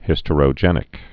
(hĭstə-rō-jĕnĭk)